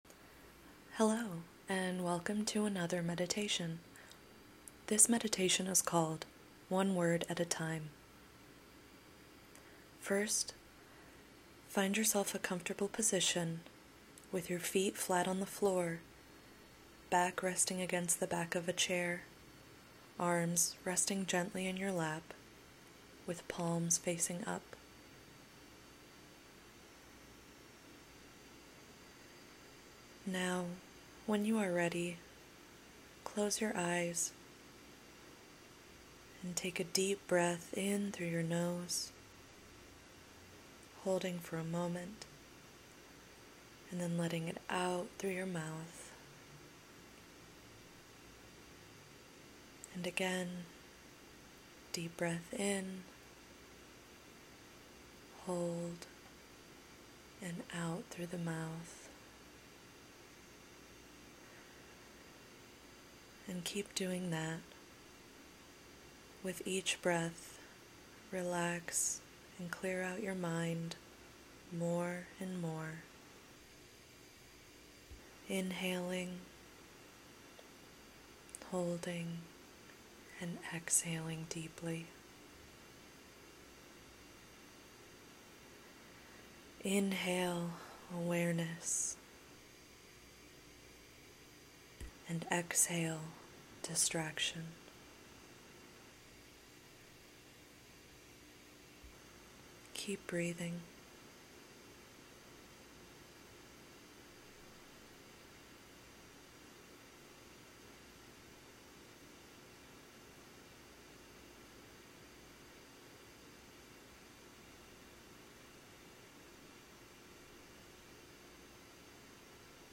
May Resource Of The Month One Word At A Time Meditation  This 5 minute meditation brings attention to the breath before inviting you to notice your reactions to a set of words.